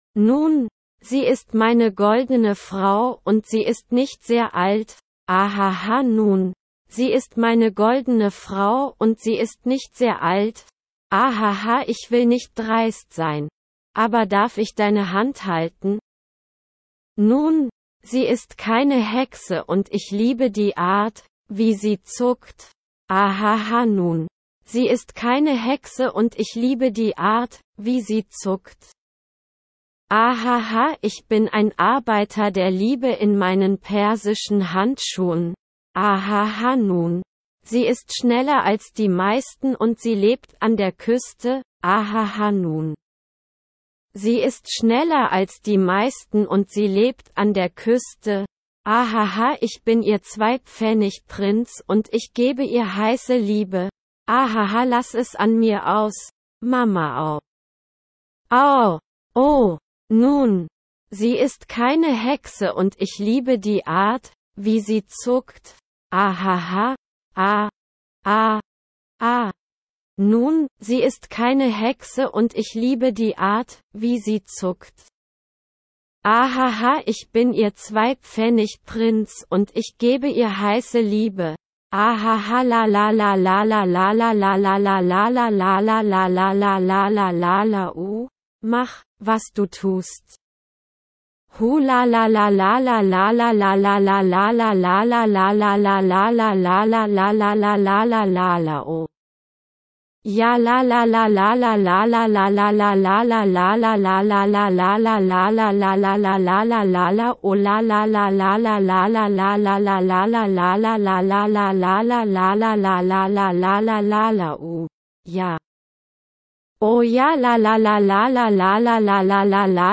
Ich habe den offiziellen Lyriktext eines Liedes genommen, ihn mit Google Übersetzer übersetzen lassen und dann die Audioausgabe, die der Dienst auch liefert, aufgezeichnet und das ist rausgekommen: